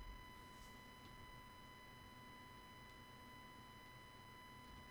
Samson usb meteor mic whitenoise
But I have found there is a constant Hiss / White noise.
A whining sound in addition to the noise?
It is down in the -45dB range which is pretty good for a cheap microphone, but it has the singular problem of sounding at the same pitch as children screaming on a jet.